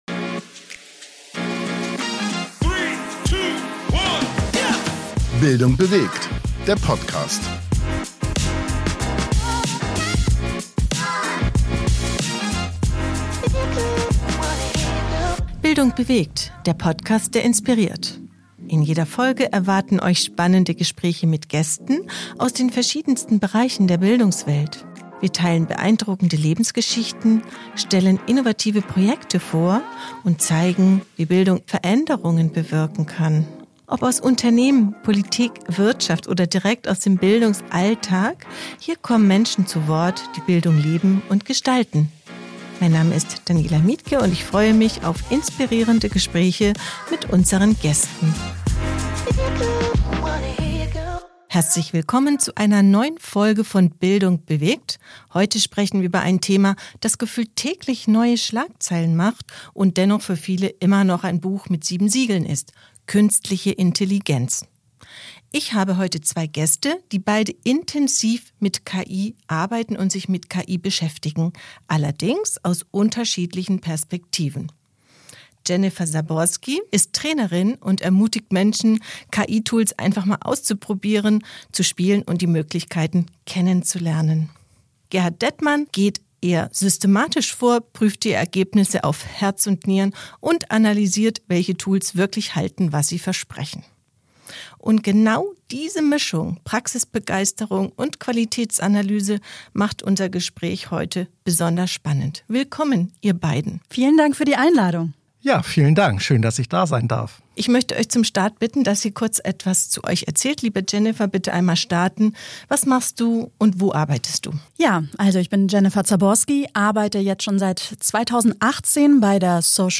KI im Gespräch – Praxis trifft Analyse  Künstliche Intelligenz ist überall – in den Schlagzeilen, in Diskussionen und zunehmend in unserem Alltag.